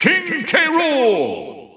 The announcer saying King K. Rool's name in English releases of Super Smash Bros. Ultimate.
King_K._Rool_English_Announcer_SSBU.wav